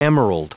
Prononciation du mot emerald en anglais (fichier audio)
Prononciation du mot : emerald